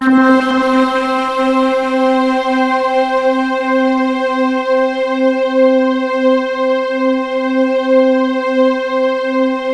POLYSYNTC4-R.wav